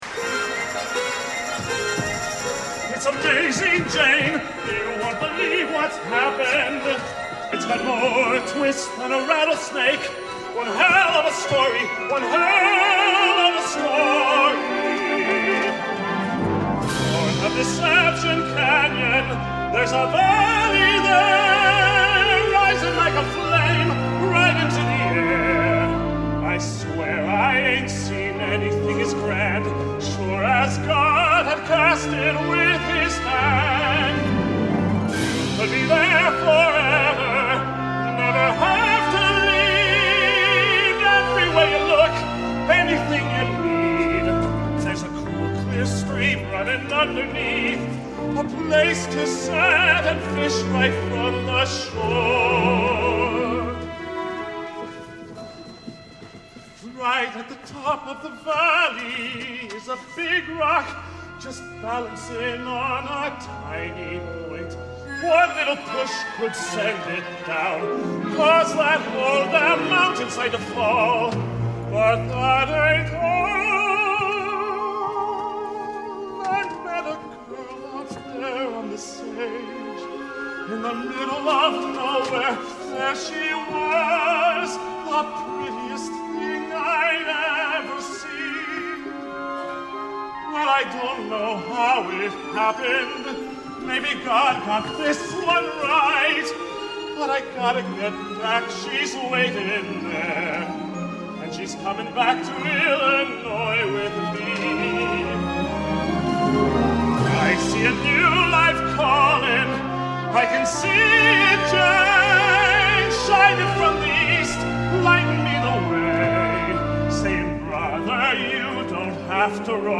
Voicing: Tenor Voice and Piano